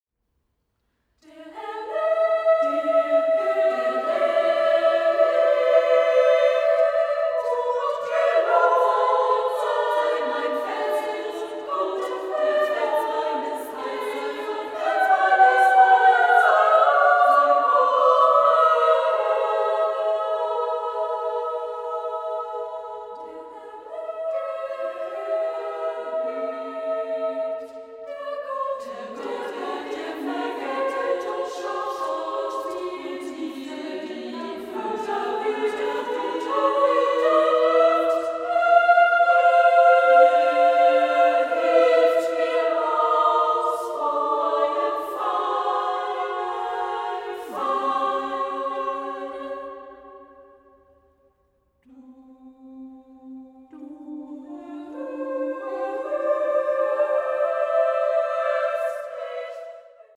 Works for Women’s and Children’s Choir and Solo Songs